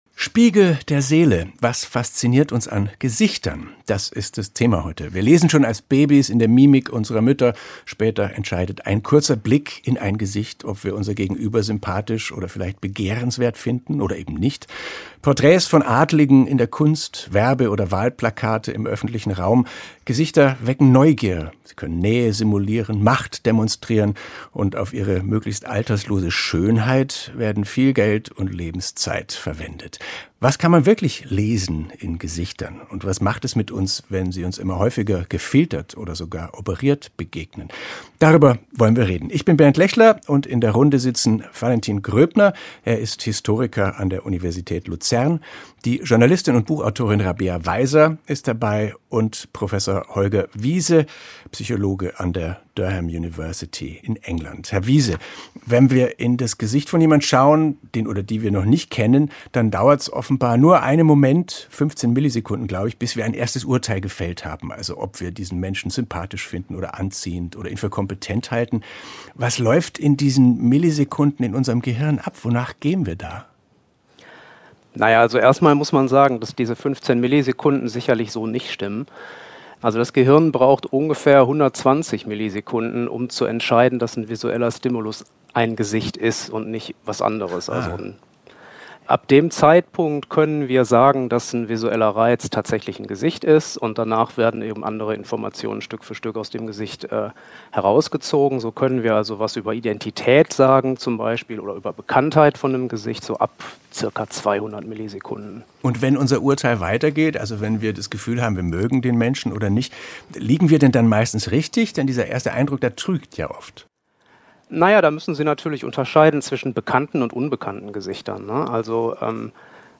Historiker
Journalistin und Buchautorin
Psychologe